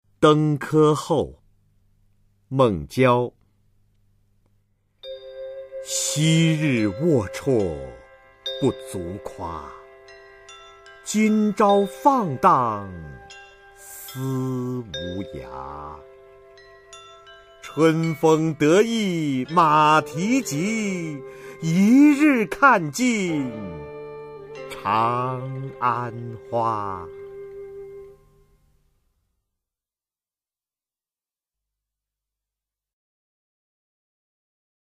[隋唐诗词诵读]孟郊-登科后 配乐诗朗诵